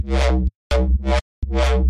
更深的电子摇摆低音
Tag: 126 bpm Electro Loops Bass Wobble Loops 328.30 KB wav Key : C